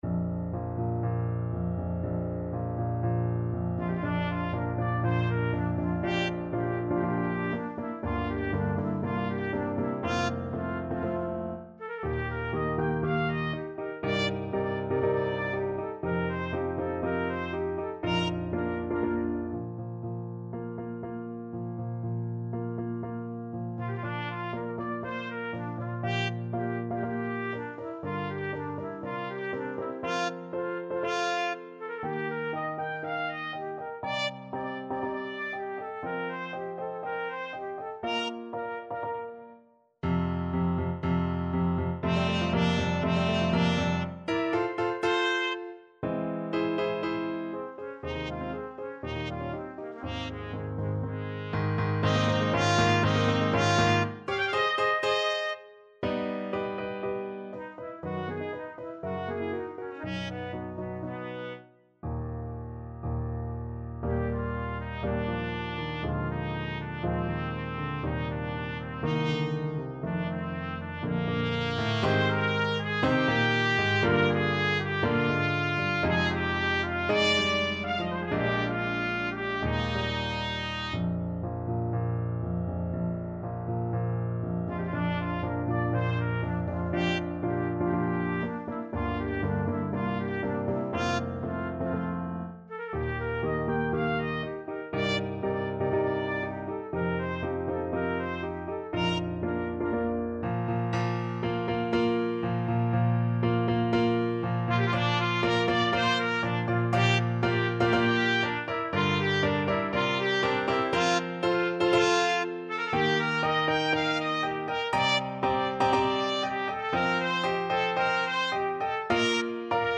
Classical Grieg, Edvard Wedding Day at Troldhaugen from Lyric Pieces Op.65 Trumpet version
Trumpet
4/4 (View more 4/4 Music)
~ = 120 Tempo di Marcia un poco vivace
Bb major (Sounding Pitch) C major (Trumpet in Bb) (View more Bb major Music for Trumpet )
Classical (View more Classical Trumpet Music)